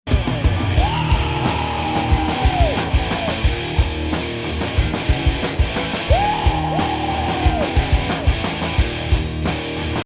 une fois sans compression=>